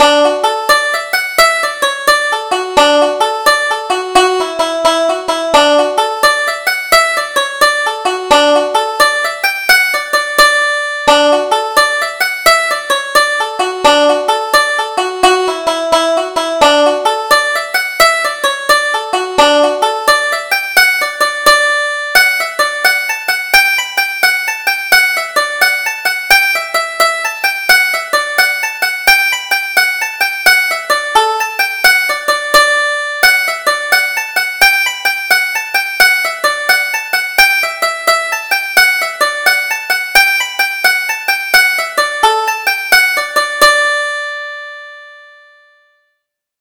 Double Jig: Jackson's Fancy